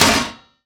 metal_impact_light_thud_03.wav